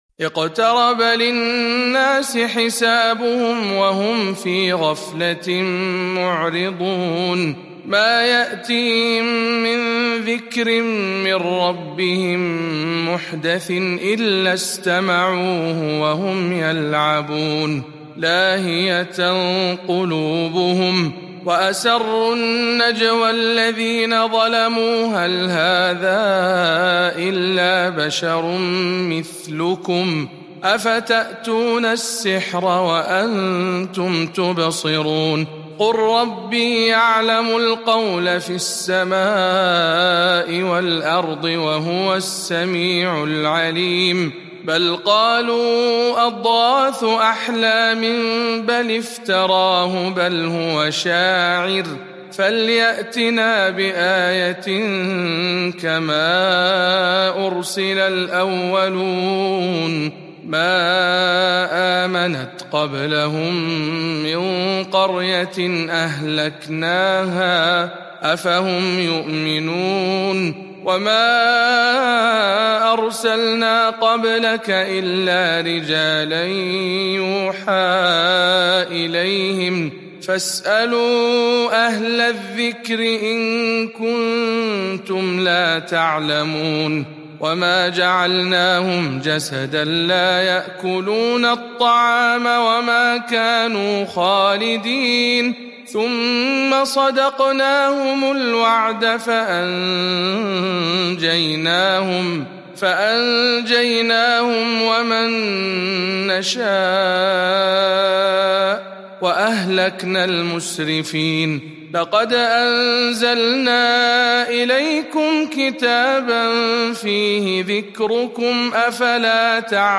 سورة الأنبياء برواية شعبة عن عاصم